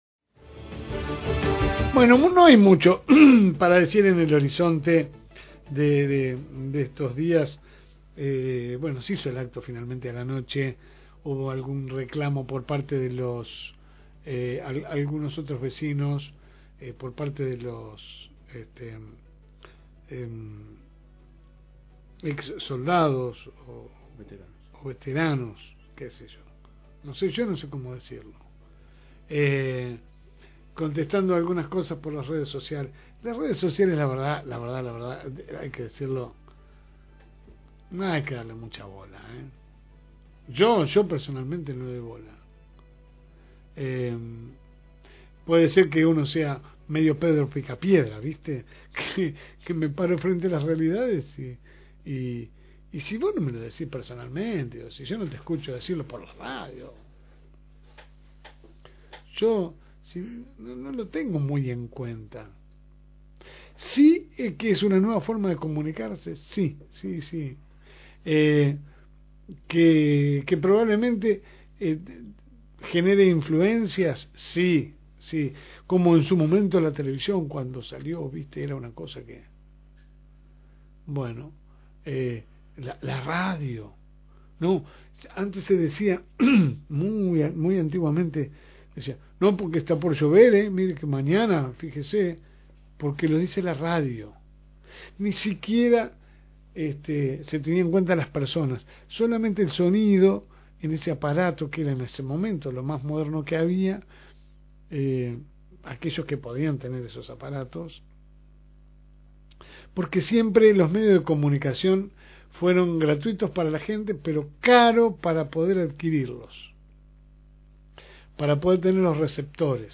Audio – Editorial – FM Reencuentro